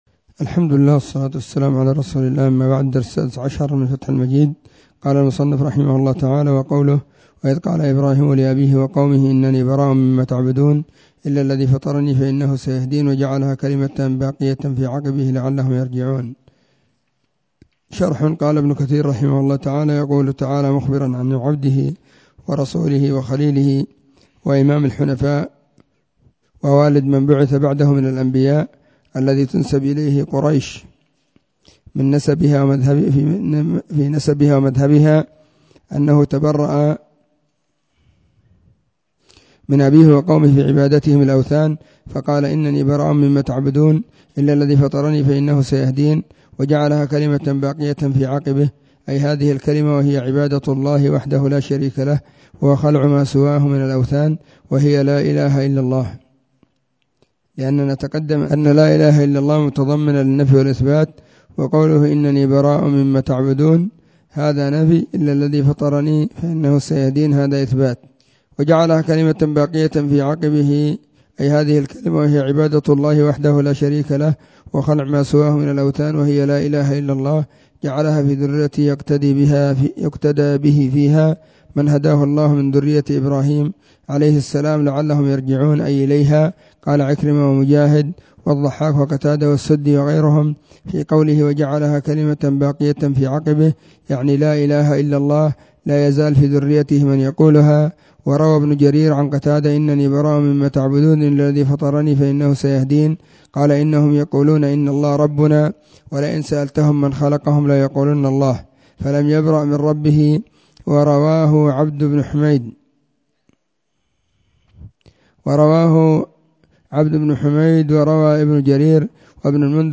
📢 مسجد الصحابة – بالغيضة – المهرة، اليمن حرسها الله.
فتح_المجيد_شرح_كتاب_التوحيد_الدرس_16.mp3